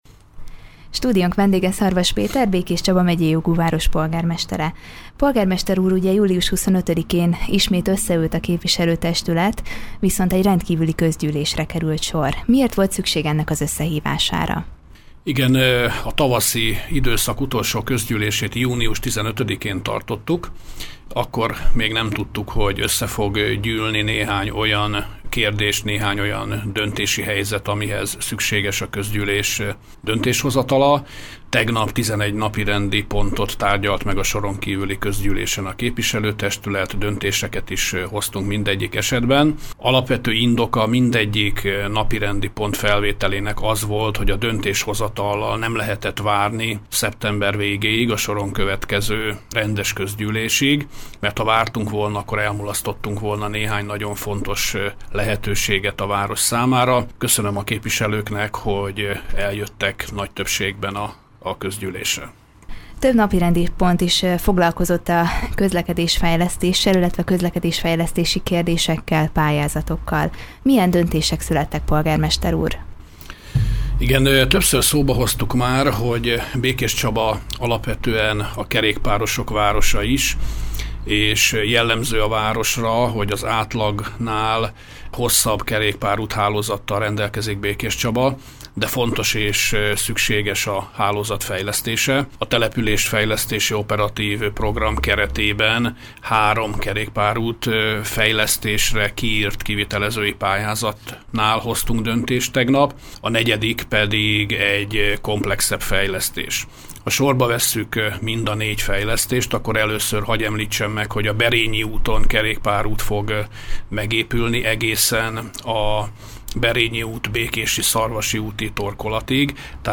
Rendkívüli közgyűlésen ülésezett Békéscsaba képviselő testülete. 10 napirendi pontot tárgyaltak, például a Békéscsaba-Szabadkígyós közötti kerékpárút hálózat fejlesztését. Bővebben az interjúban hallhatnak.